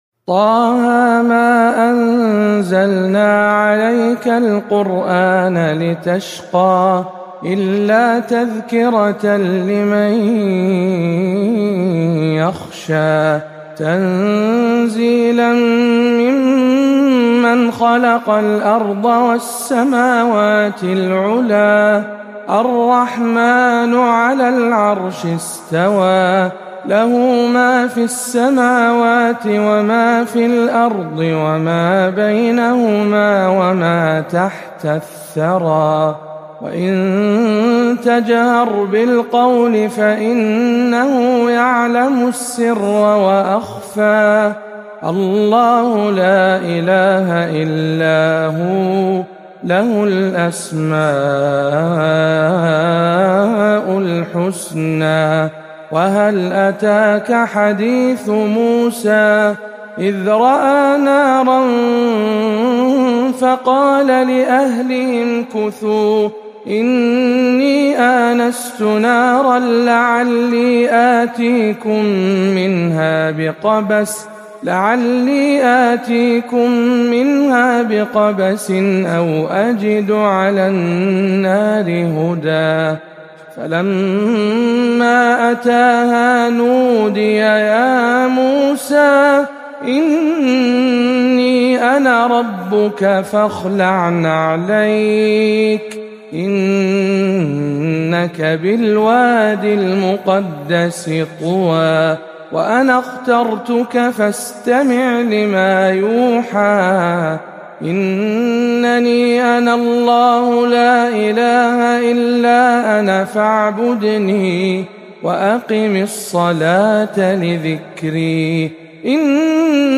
سورة طه بمسجد الحسين بن علي بخليص - رمضان 1439 هـ